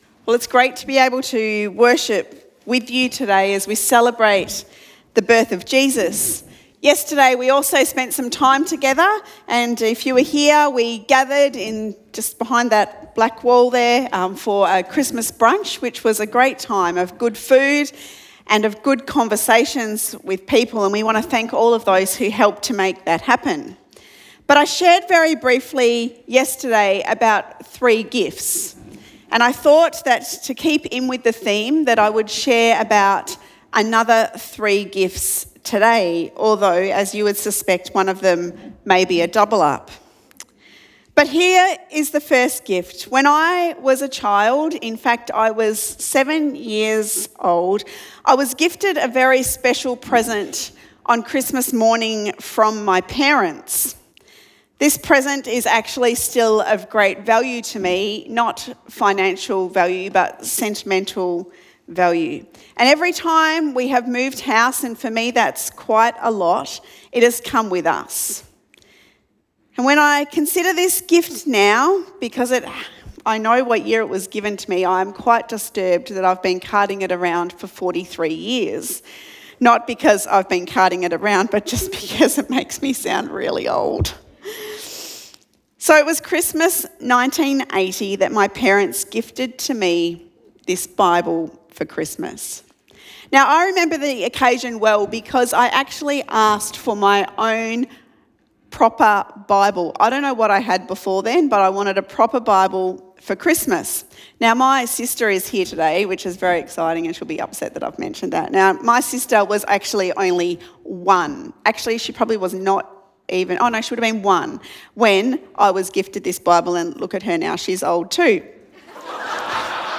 Sermon Podcasts The Gift 2023